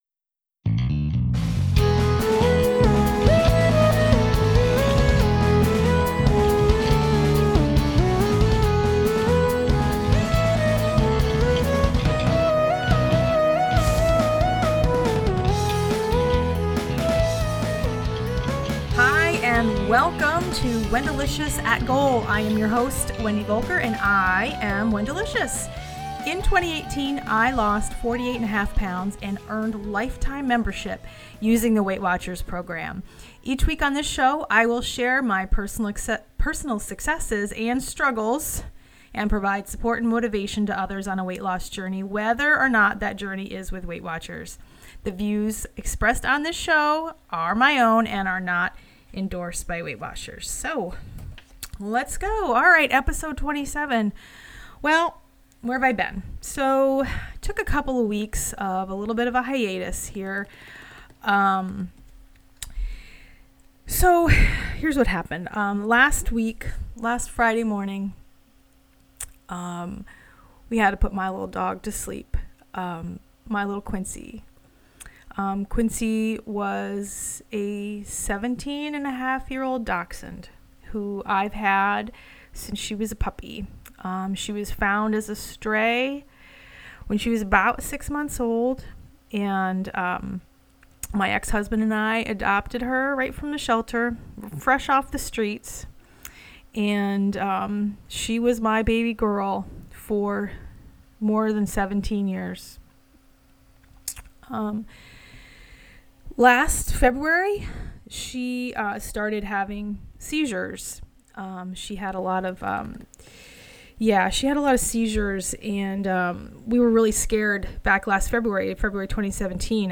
This episode was recorded as a Facebook LIVE video.
But, there’s some fun in this episode too – secret shame foods and guilty pleasures! Lots of great audience interaction from the live feed.